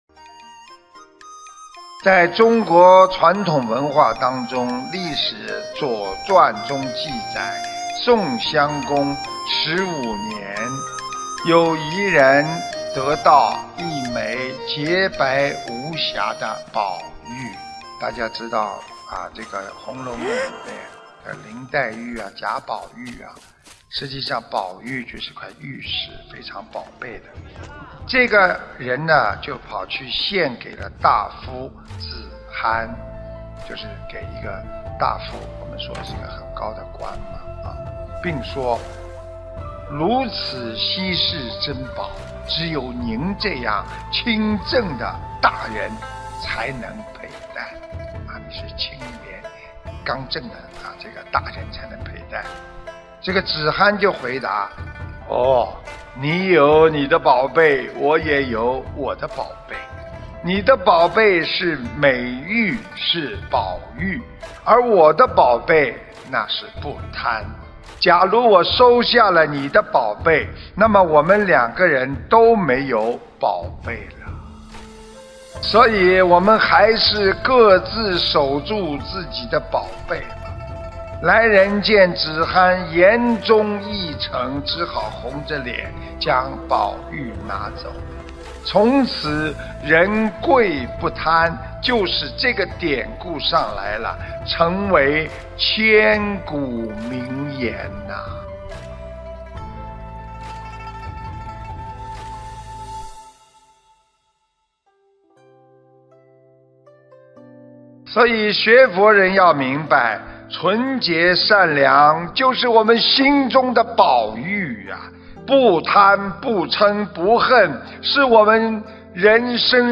音频：子罕巧妙回绝敬献的宝玉！人贵不贪！澳大利亚墨尔本师父开示2019年12月06日！